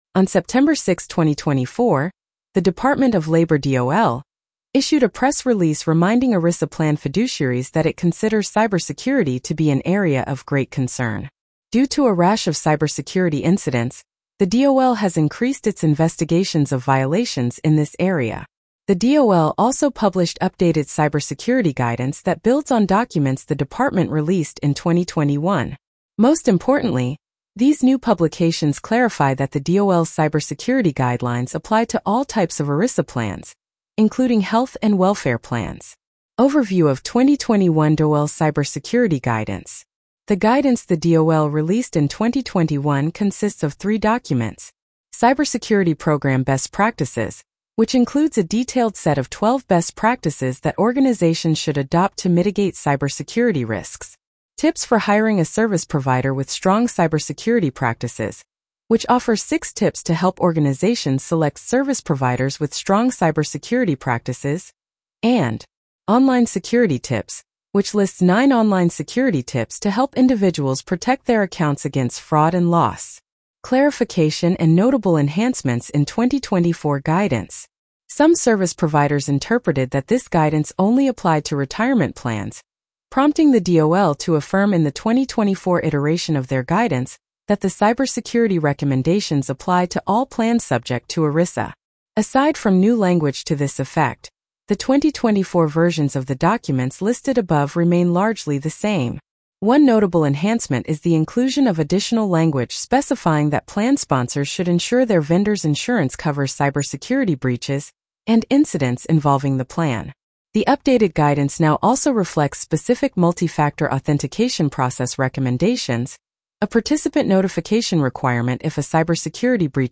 Cybersecurity and ERISA Blog Narration.mp3